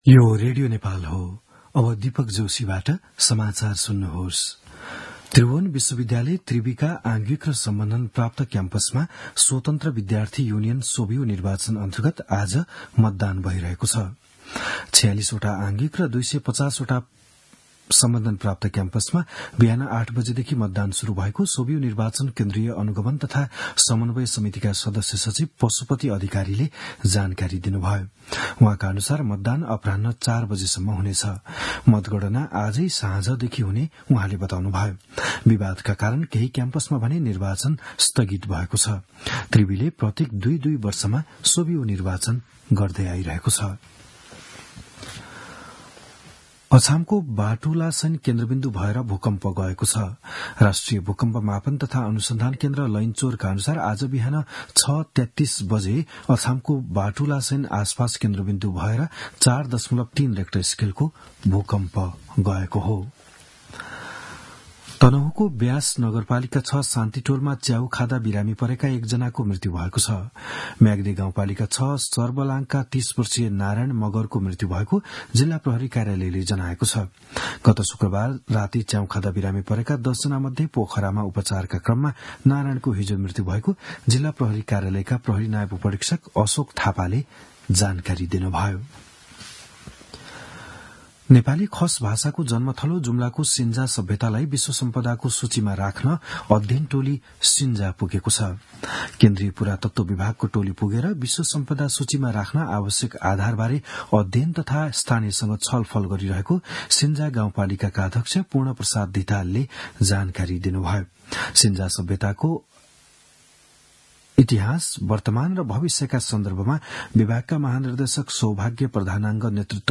बिहान ११ बजेको नेपाली समाचार : ५ चैत , २०८१
11-am-news-1-4.mp3